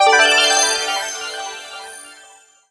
get_pickup_02.wav